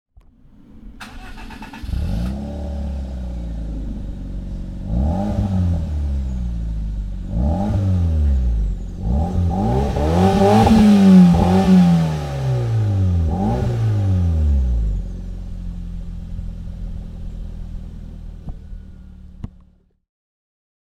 Daihatsu Copen (2004) - Starten und Leerlauf
Daihatsu_Copen.mp3